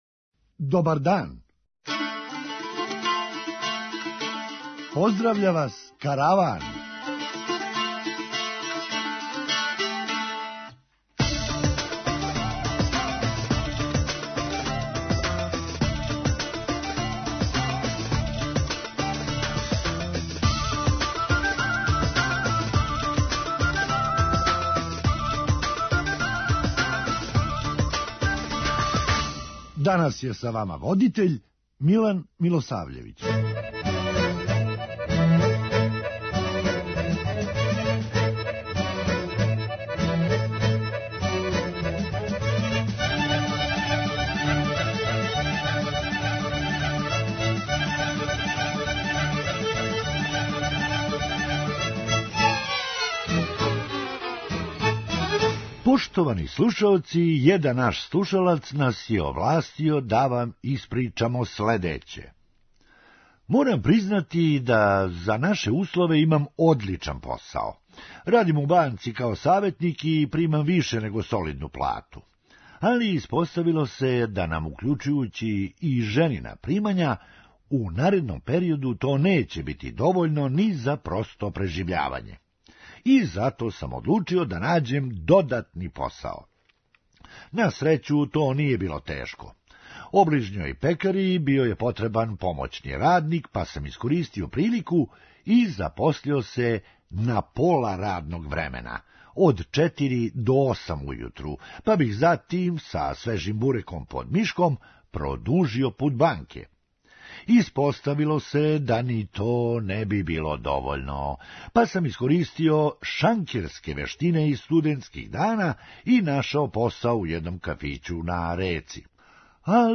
Хумористичка емисија
Проблем је у томе што су одмах по објављивању ове вести, мушкарци покуповали све карте до краја сезоне. преузми : 9.13 MB Караван Autor: Забавна редакција Радио Бeограда 1 Караван се креће ка својој дестинацији већ више од 50 година, увек добро натоварен актуелним хумором и изворним народним песмама.